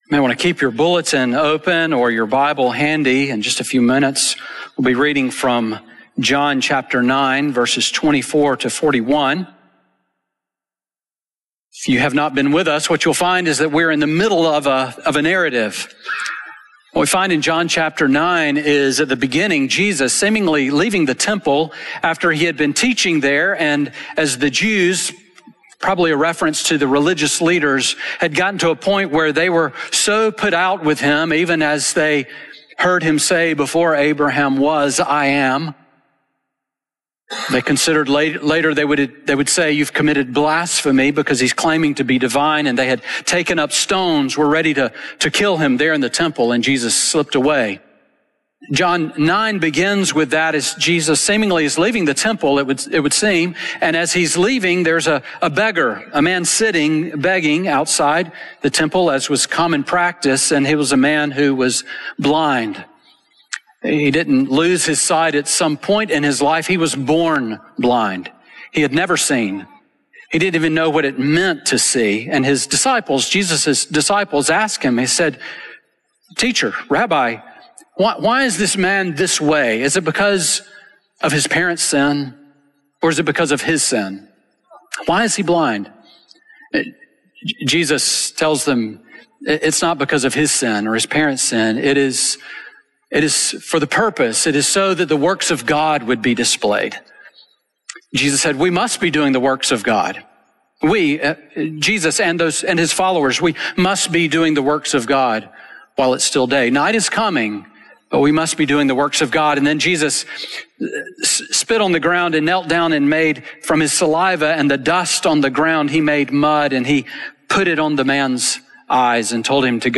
Sermon on John 9:24-41 from March 1, 2026